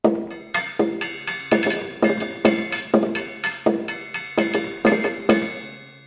baraban
barabanek.mp3